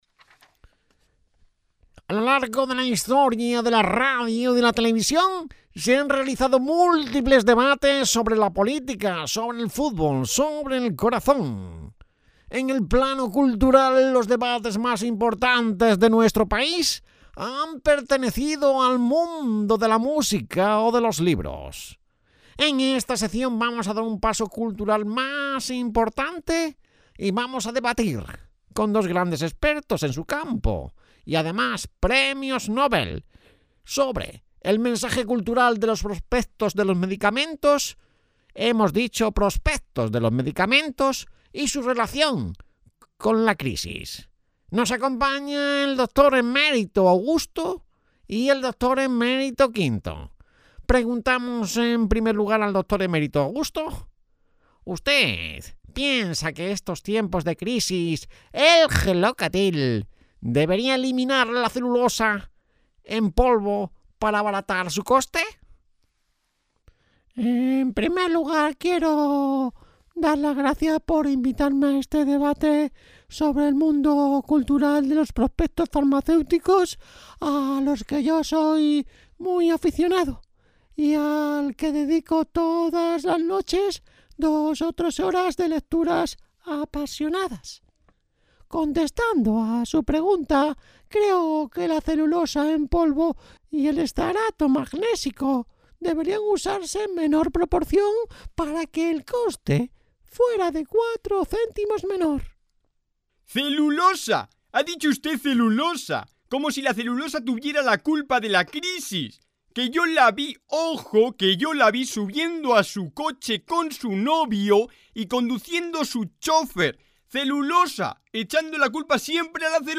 Debate farmacéutico sobre prospectos de medicamentos.
medicamentos_guion_radio_television.mp3